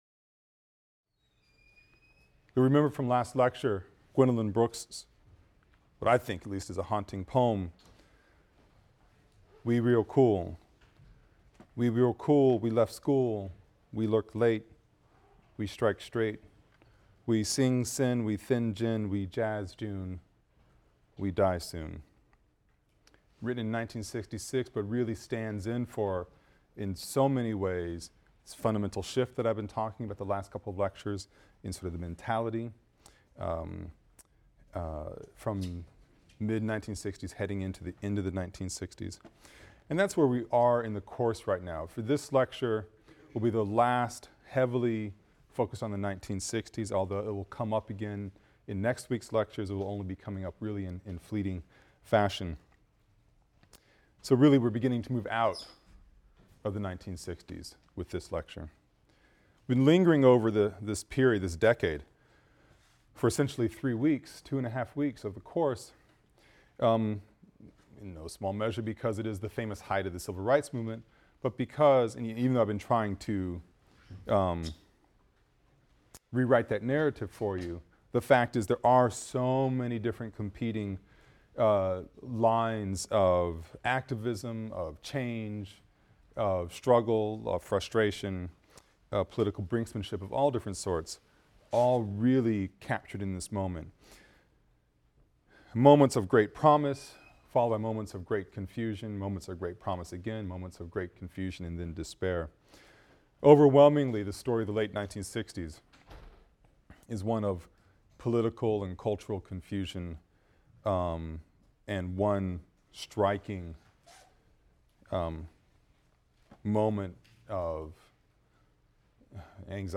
AFAM 162 - Lecture 19 - Black Power (continued) | Open Yale Courses